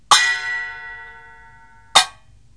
Chũm Chọe
hi đánh Chũm Chọe, hai tay cầm hai núm, dập hai mặt vào nhau, có lúc đập chéo xuống, chéo lên, người sử dụng Chũm Chọe vừa đánh vừa múa .
Cheng - Chập: